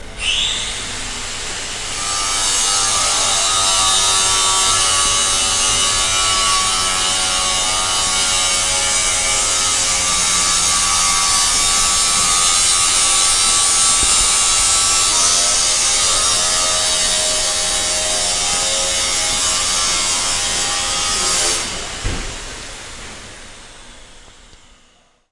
手持式研磨机2
描述：手持式研磨机切割金属的声音